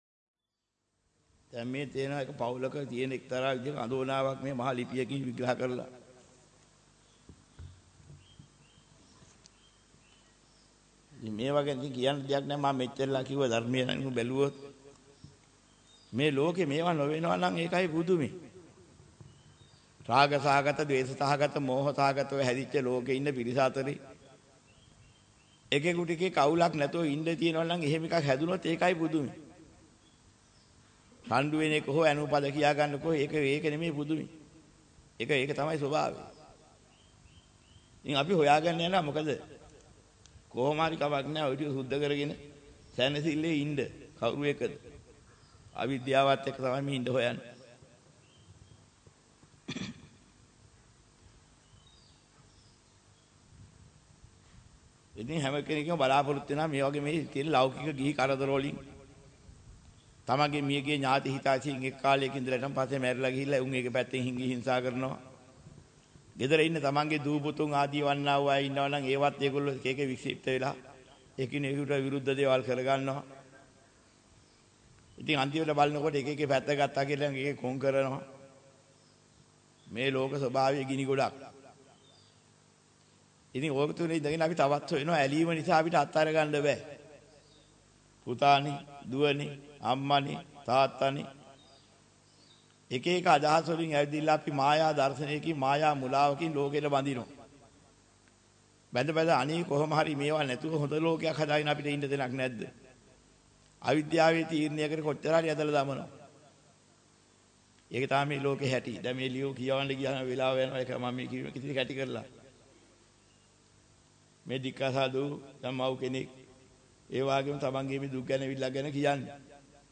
වෙනත් බ්‍රව්සරයක් භාවිතා කරන්නැයි යෝජනා කර සිටිමු 24:14 10 fast_rewind 10 fast_forward share බෙදාගන්න මෙම දේශනය පසුව සවන් දීමට අවැසි නම් මෙතැනින් බාගත කරන්න  (10 MB)